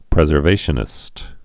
(prĕzər-vāshə-nĭst)